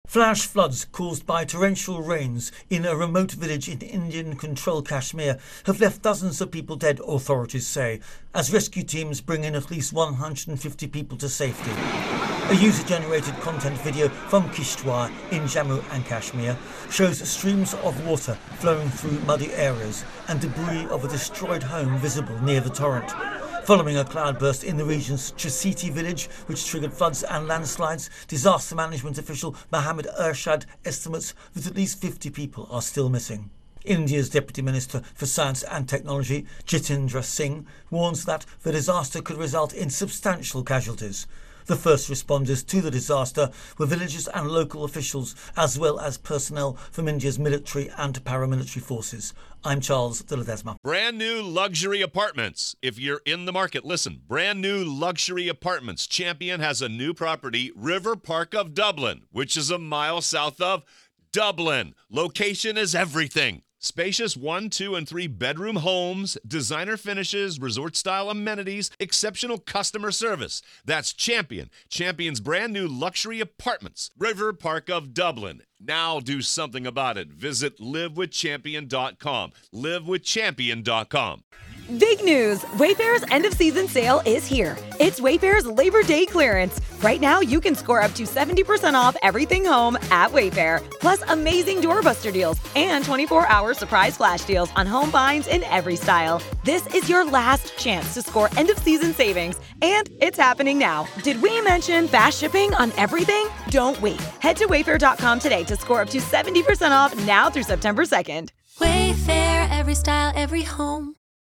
Latest Stories from The Associated Press / Torrential rains trigger deadly flash floods in Kashmir, killing at least 37